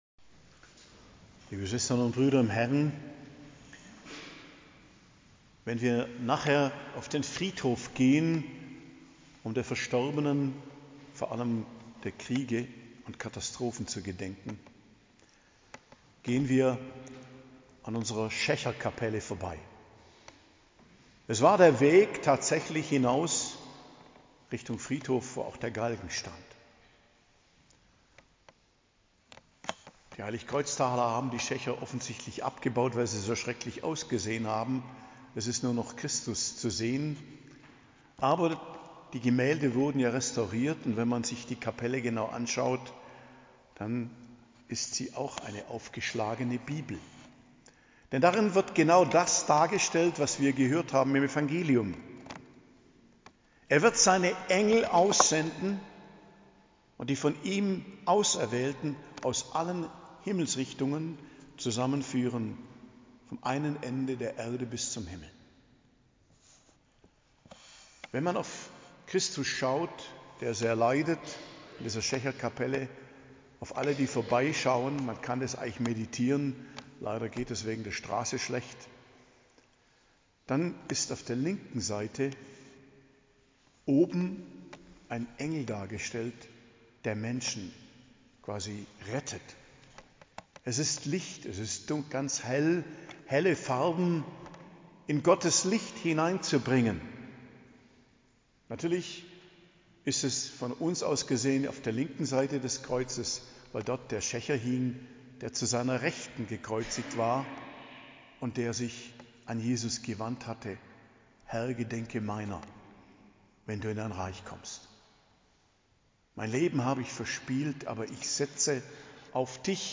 Predigt zum 33. Sonntag im Jahreskreis, 17.11.2024 ~ Geistliches Zentrum Kloster Heiligkreuztal Podcast